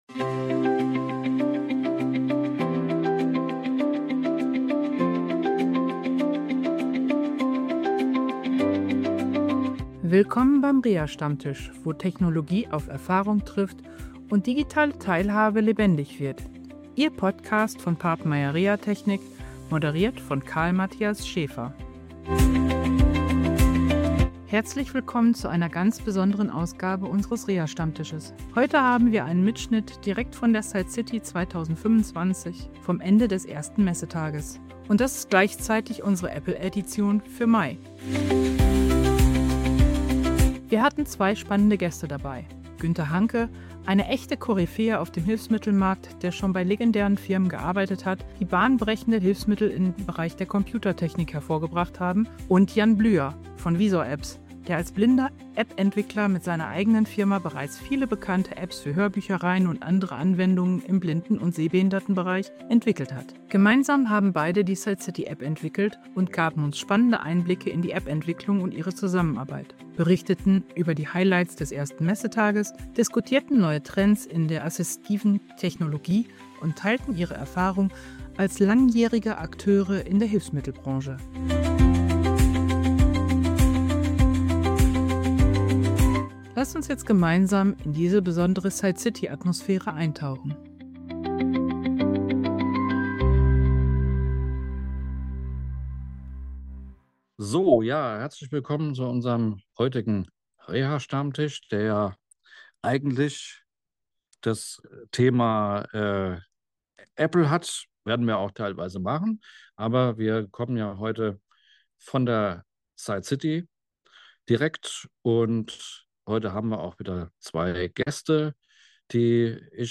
Beschreibung vor 9 Monaten Reha Stammtisch SightCity-Spezialfolge In dieser besonderen Apple-Edition des Reha Stammtischs sprechen wir mit zwei Experten der assistiven Technologie direkt von der SightCity 2025 in Frankfurt.